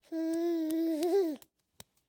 clap.ogg